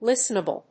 音節lís・ten・a・ble 発音記号・読み方
/‐nəbl(米国英語)/